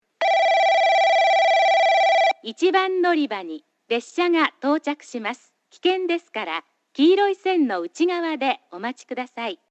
☆旧放送
1番のりば接近放送　女声 放送はJACROS簡易型でした。
スピーカーはソノコラム（ミニ）でした。